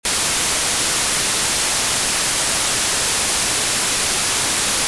A.9 Rumore Bianco Uniforme